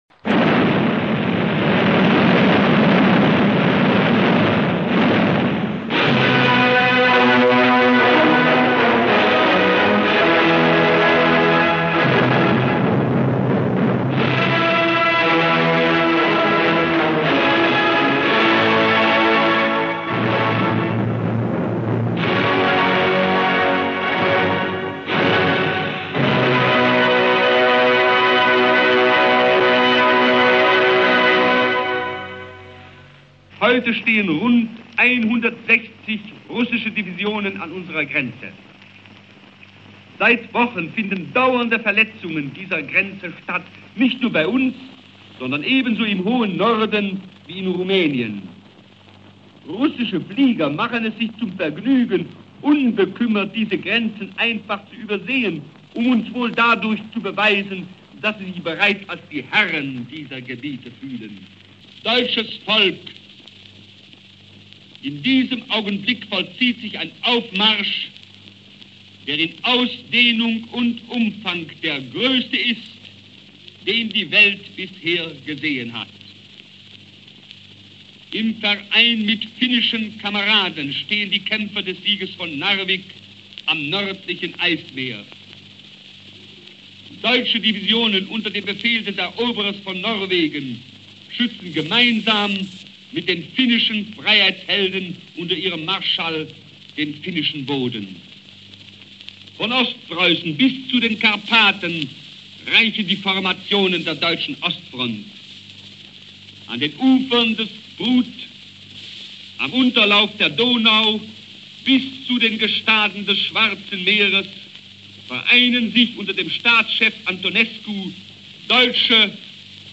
Beginn des Stückes - Propagandarede von Josef Goebbels, 1941, in der Wochenschau, aus dem Volksempfänger als mp3. Anfang mit der sogenannten Rußlandfanfare, die, nach dem Beginn des Angriffes auf die Sowjetunion, die Wochenschauen einleitete.